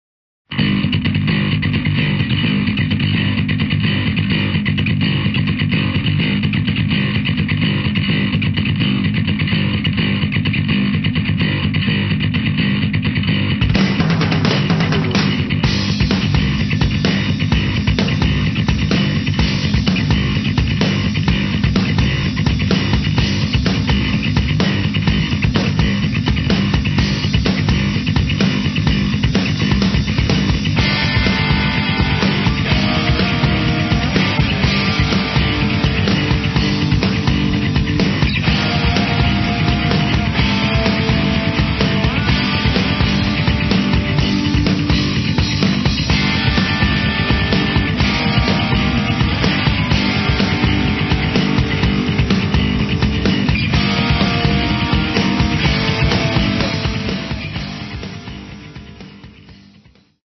spremljevalni vokali
saksofonom